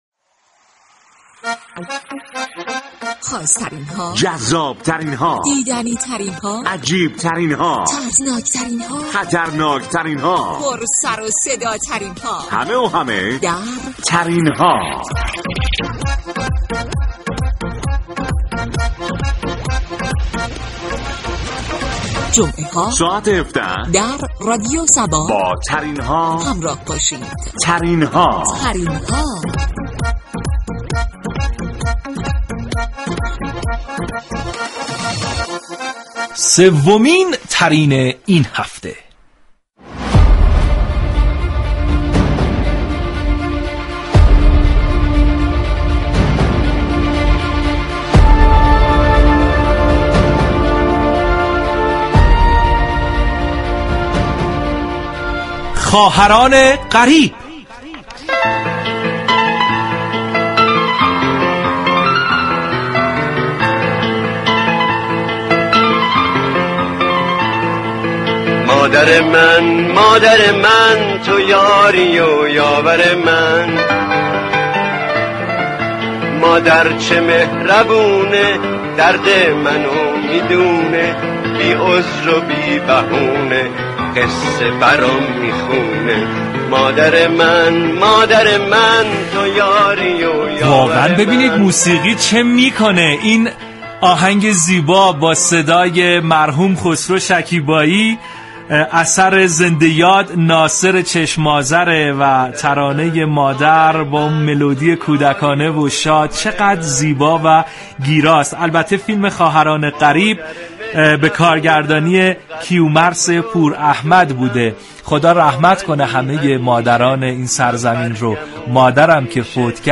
موسیقیِ نابِ ایرانی